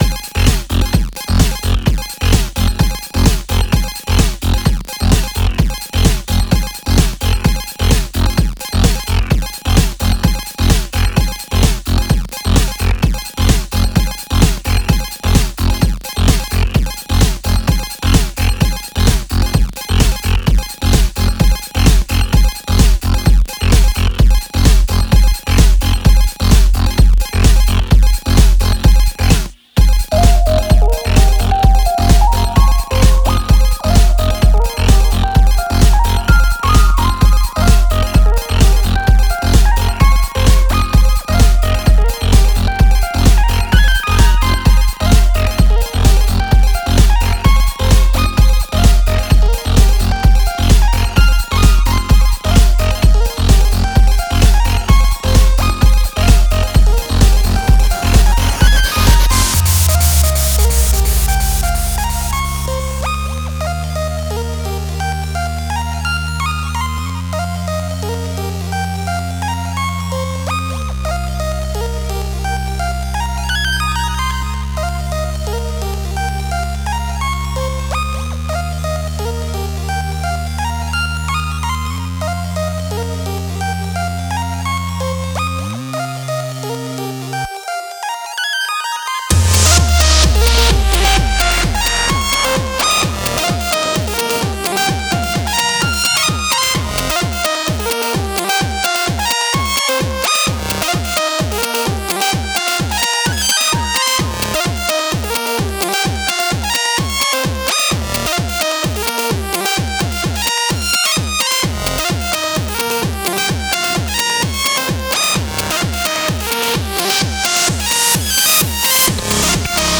Genre:#8bit